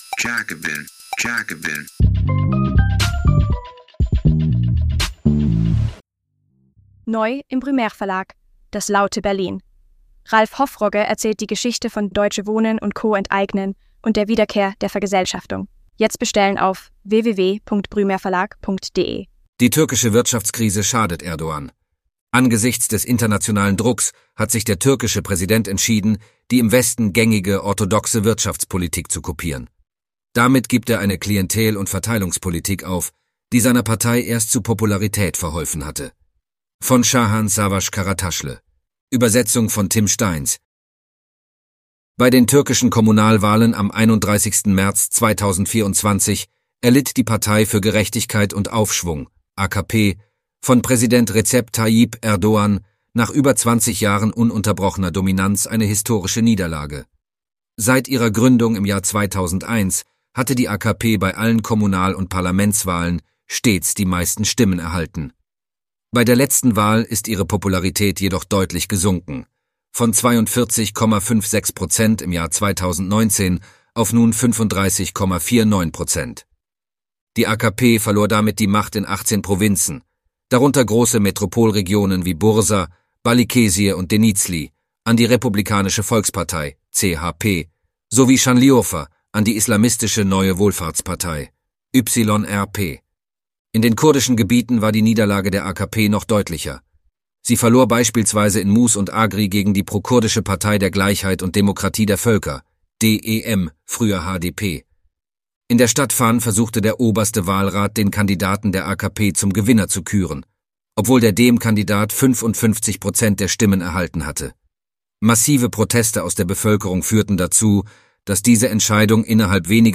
Das neue deutsch-türkische Abschiebeabkommen wird die Repression in der Türkei verstärken – Interview mit Feleknas Uca